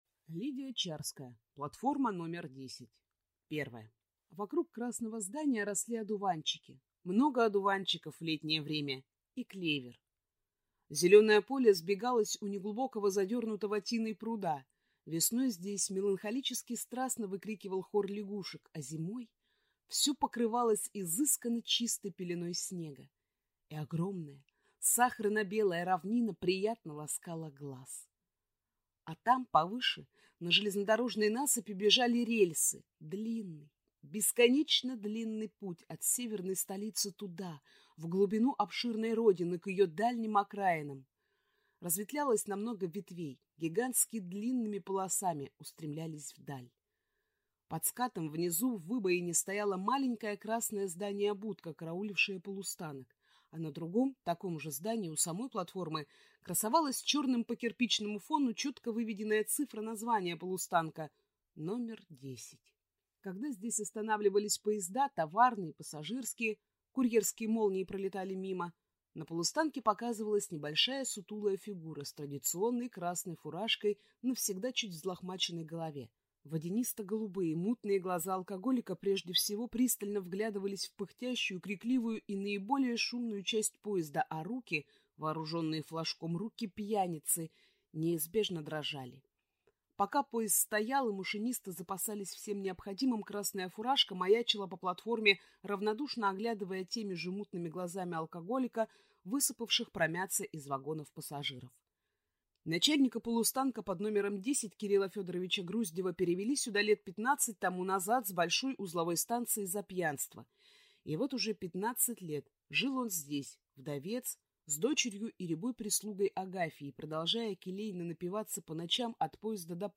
Аудиокнига Платформа № 10 | Библиотека аудиокниг
Прослушать и бесплатно скачать фрагмент аудиокниги